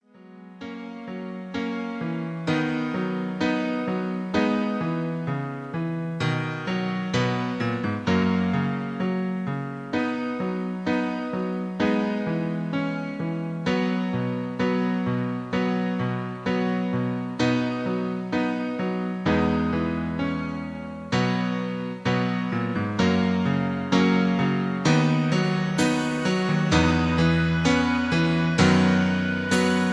karaoke collection , backing tracks